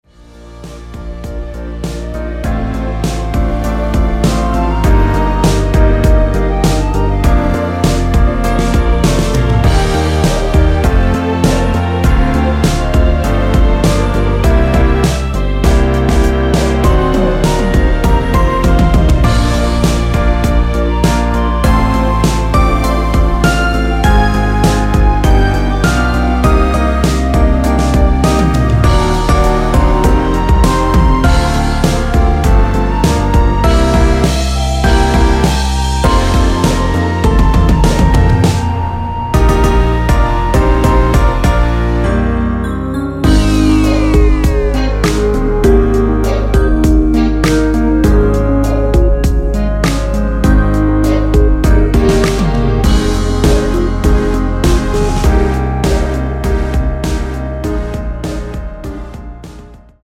원키에서(-3)내린 멜로디 포함된 MR입니다.(미리듣기 확인)
Ab
앞부분30초, 뒷부분30초씩 편집해서 올려 드리고 있습니다.